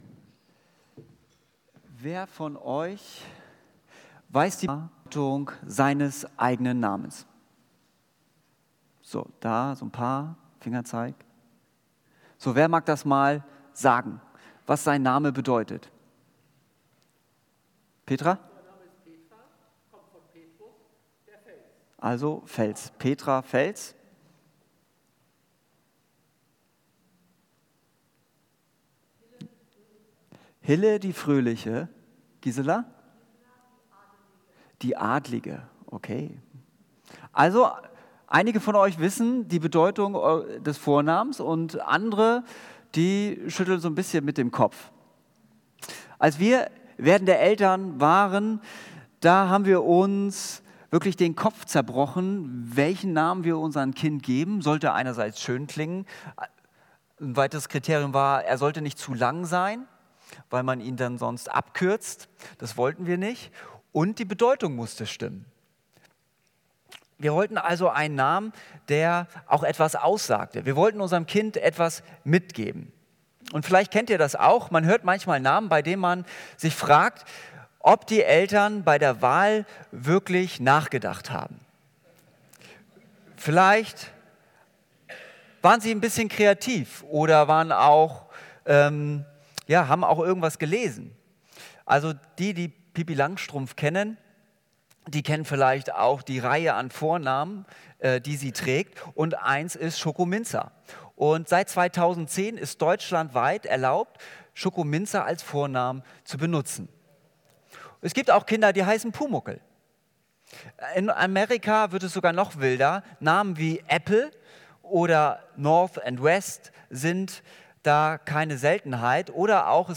Predigt Lichtblicke